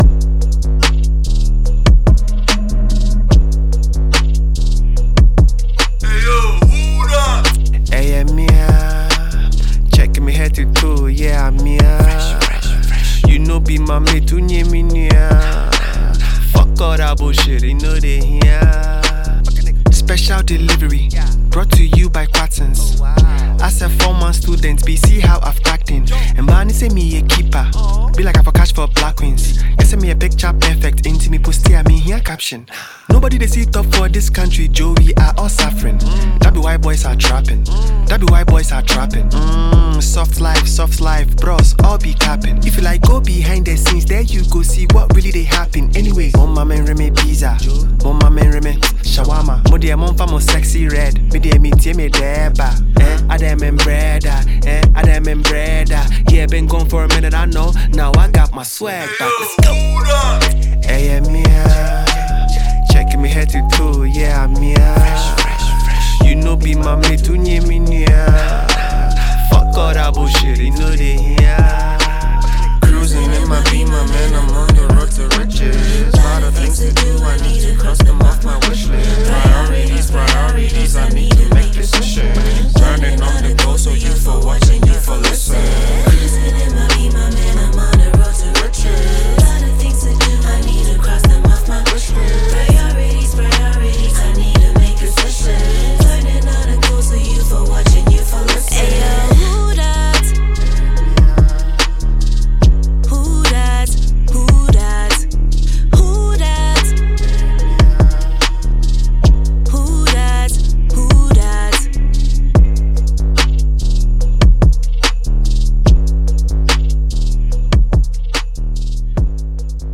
Check out the latest tune from Ghanaian rapper